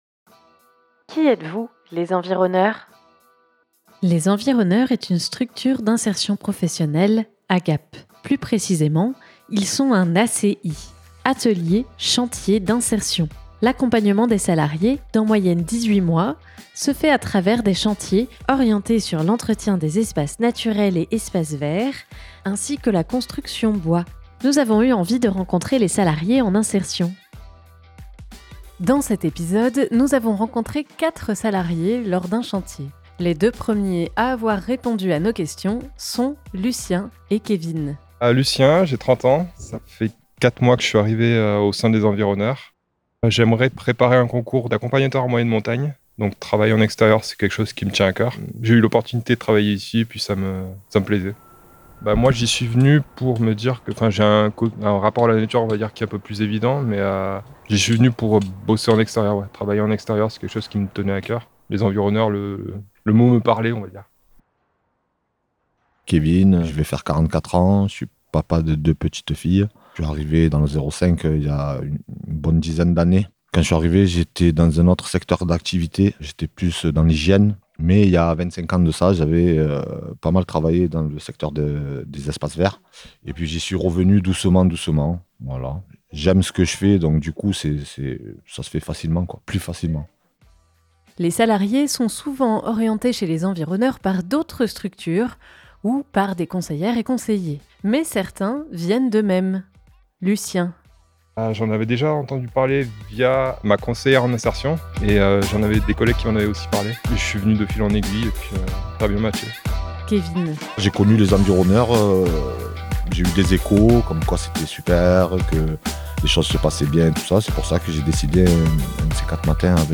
Pour ce 6e épisode, nous nous sommes rendus sur un chantier qui avait lieu pendant l'été, afin de rencontrer 4 salariés en insertion.